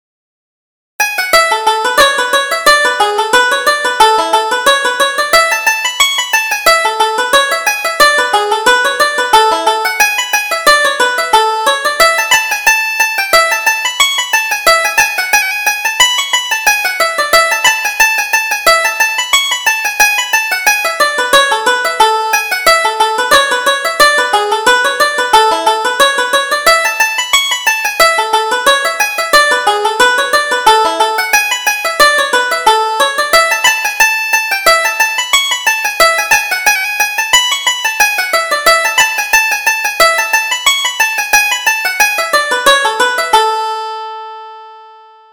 Reel: Sergt. Early's Dream